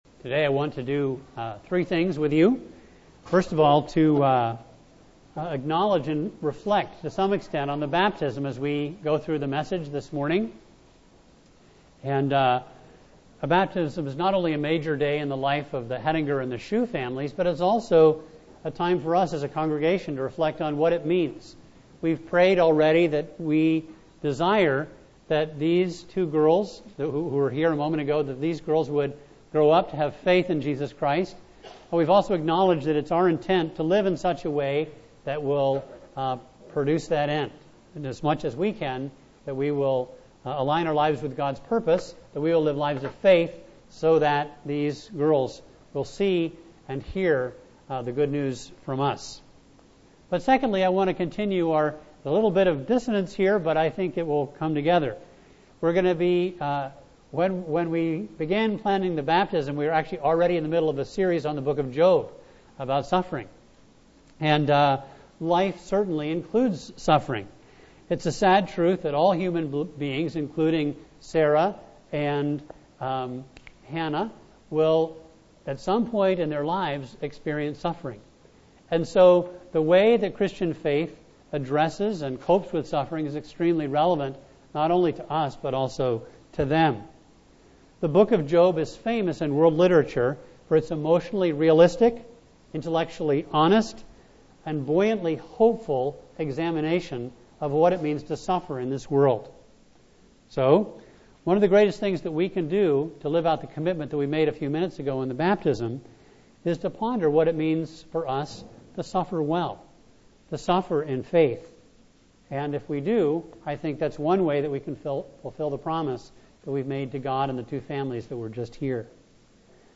A message from the series "Job."